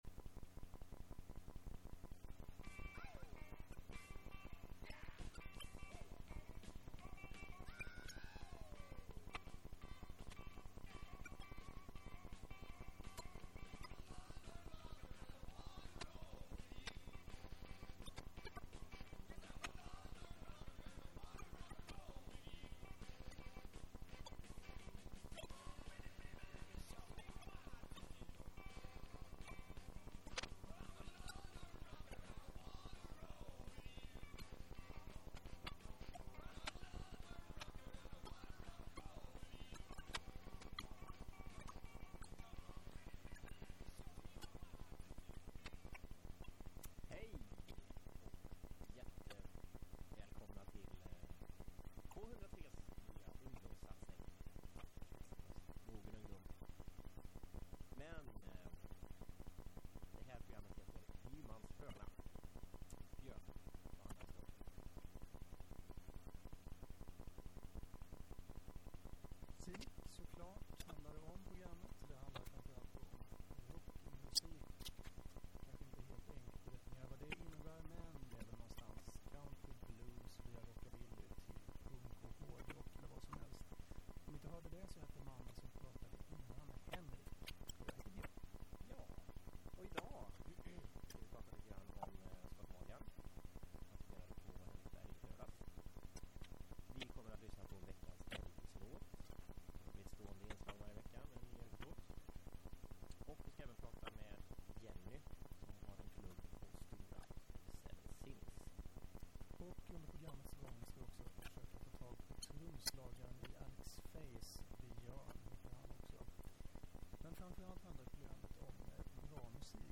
Hymans H�rna handlar om rock n' roll, hela v�gen fr�n country och blues fram till punk och h�rdrock. Det blir sprillans nytt s�v�l som knastrande gammalt, varvat med intervjuer och reportage fr�n G�teborgs musikv�rld.